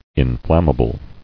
[in·flam·ma·ble]